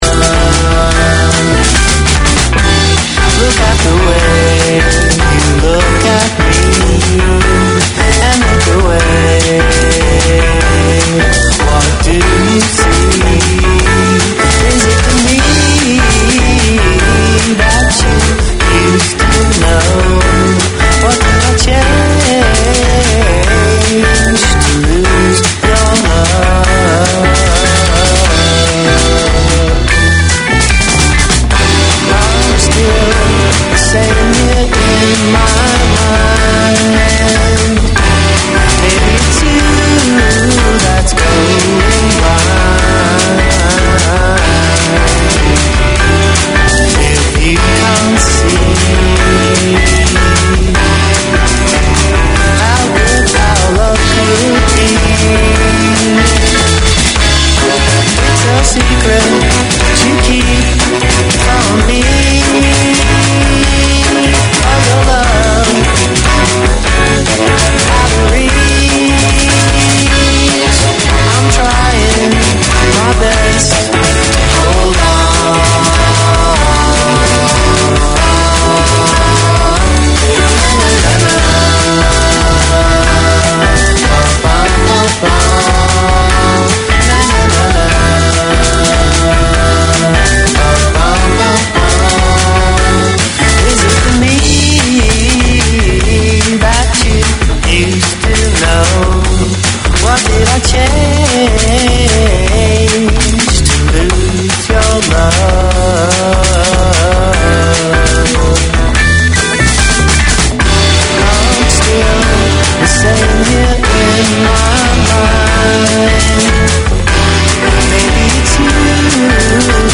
In recognition of the diversity of seniors, the hosts bring senior leaders and experts to the show to explore a wide range of topics. If you’re over 50, tune to Senior Focus for comprehensive information on the policies and events that impact on seniors’ wellbeing.